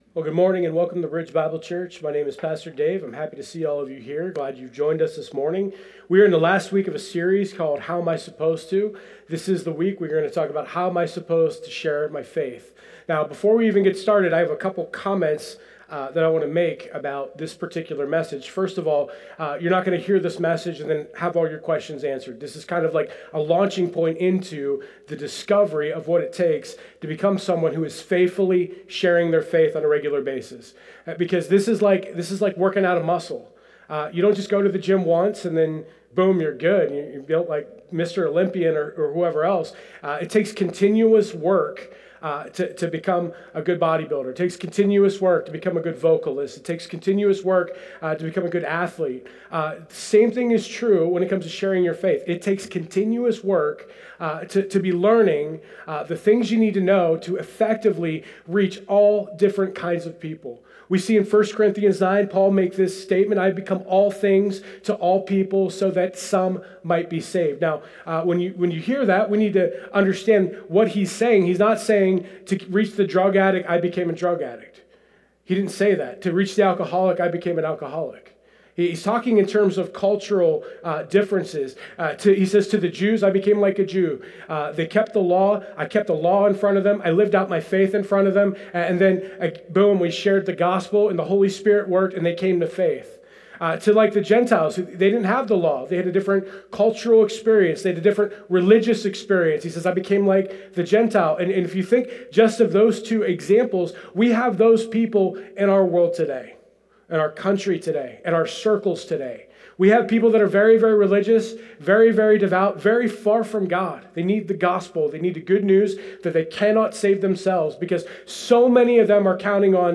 Download Download How Am I Supposed To Current Sermon How Am I Supposed to Share My Faith?